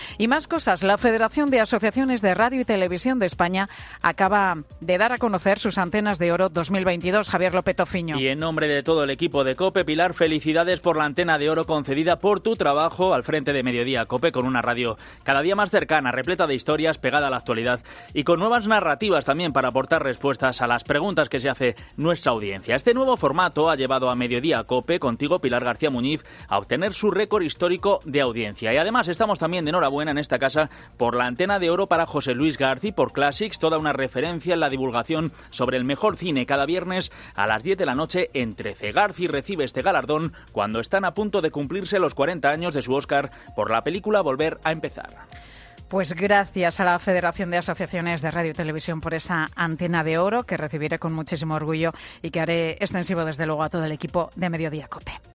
Pilar García Muñiz ha podido agradecer en directo a la audiencia de COPE el haber recibido un galardón tan prestigioso: "Gracias a la Federación de Asociaciones de Radio y Televisión por esa Antena de Oro que recibiré con mucho orgullo y haré extensiva a todo el equipo de 'Mediodía COPE', ha querido decir en directo.